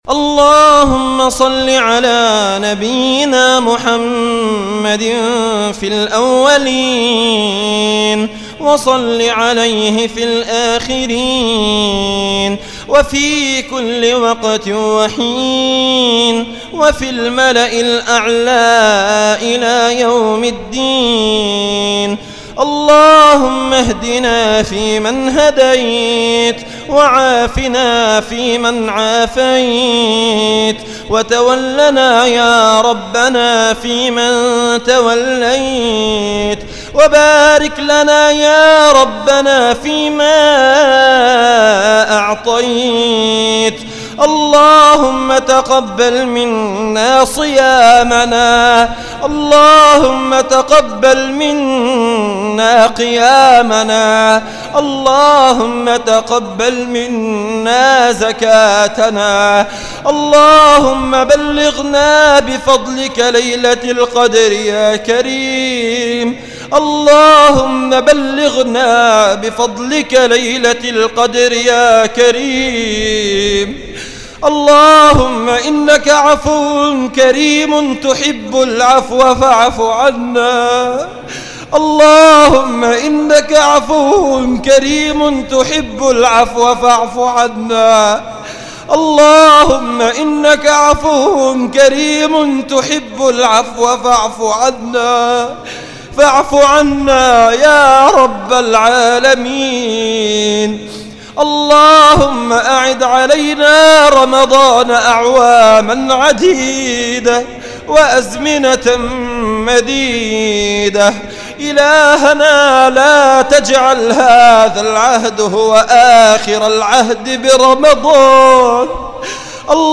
أدعية وأذكار
دعاء خاشع
تسجيل لدعاء خاشع ومؤثر